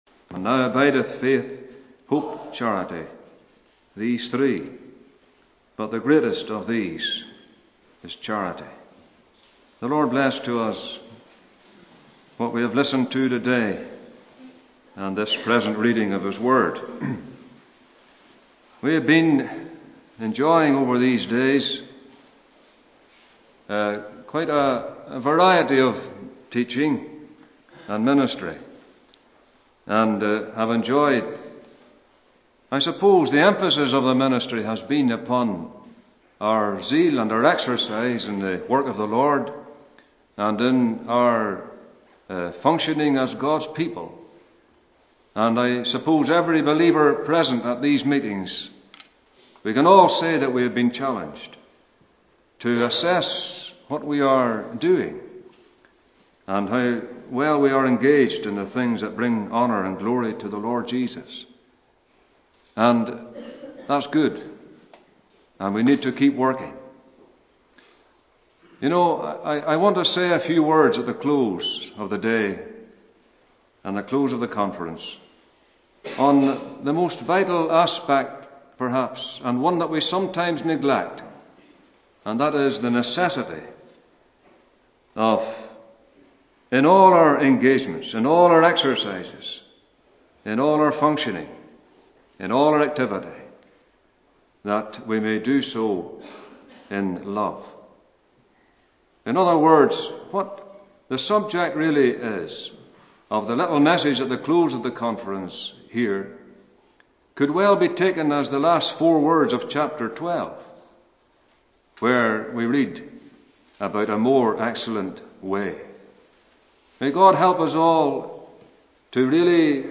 2016 Easter Conference